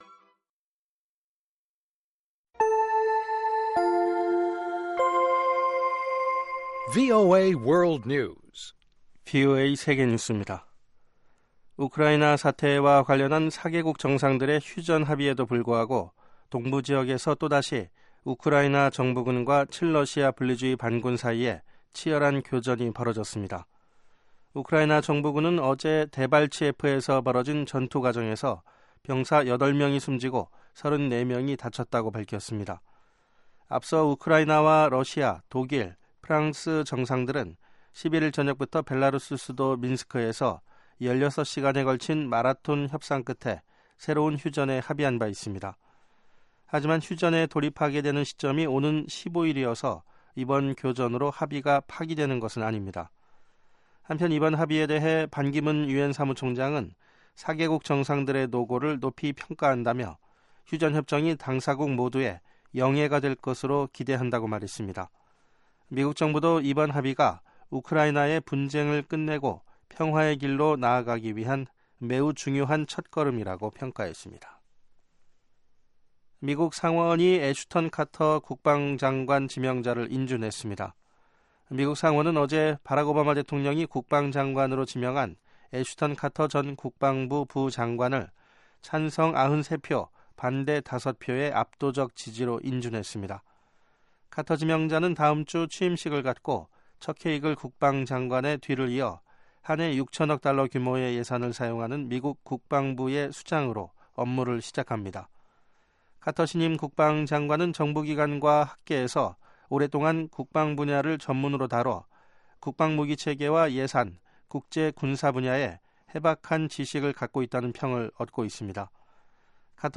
VOA 한국어 방송의 간판 뉴스 프로그램 '뉴스 투데이' 3부입니다. 한반도 시간 매일 오후 11시부터 자정까지 방송됩니다.